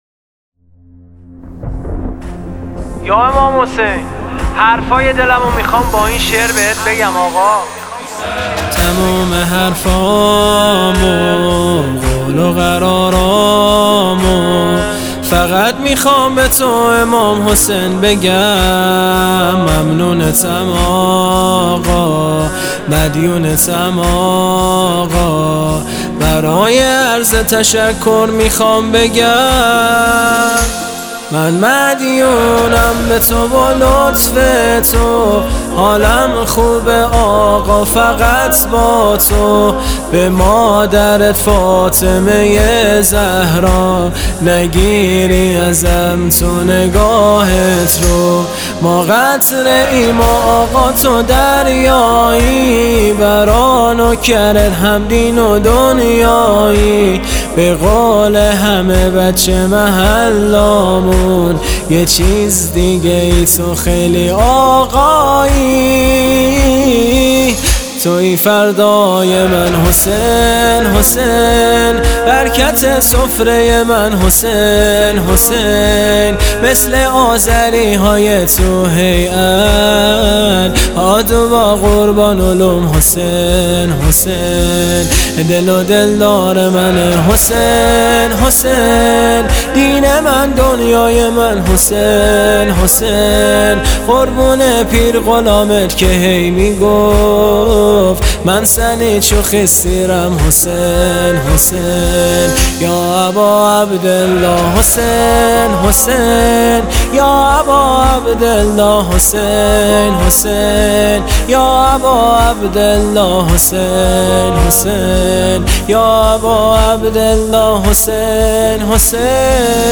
کلیپ مداحی: فقط می‌خوام به تو بگم امام حسین (ع)